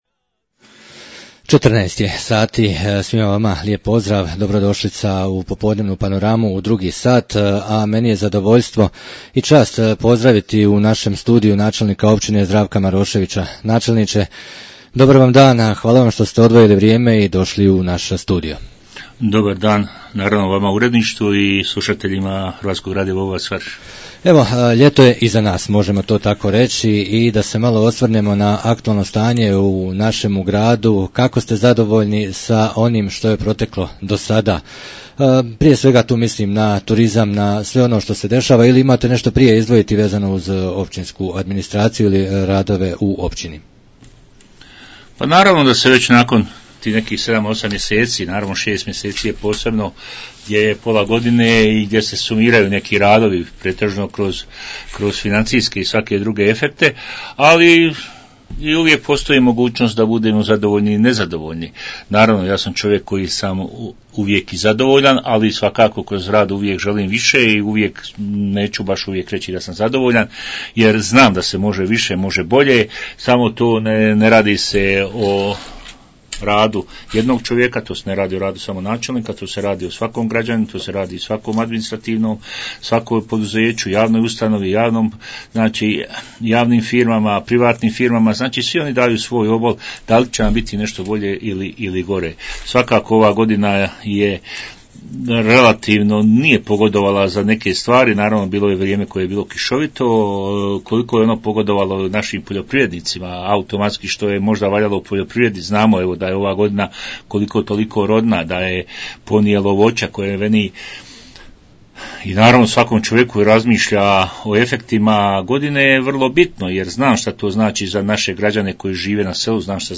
U studiju radio Bobovca ugostili smo načelnika općine Vareš Zdravka Maroševića i razgovarali o aktualnom stanju u općini Vareš, problemu snadbijevanja drvima i ostalim temama.